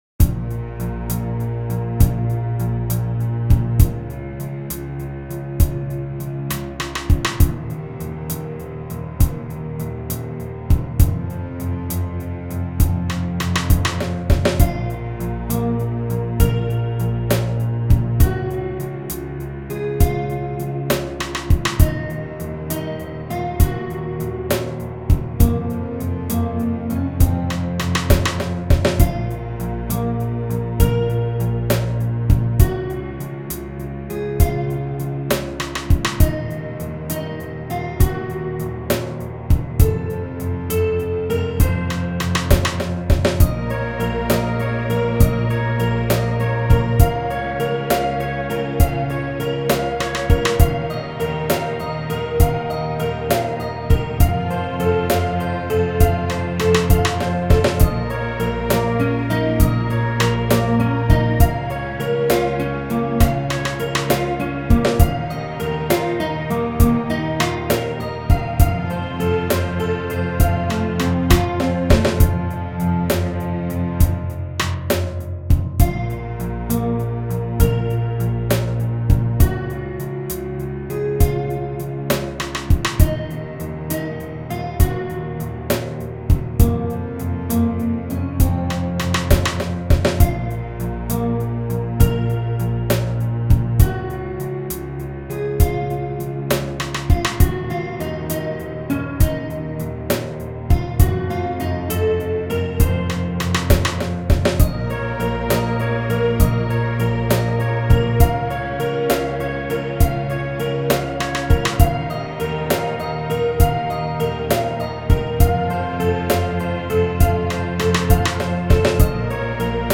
All the drums have been performed by Hydrogen.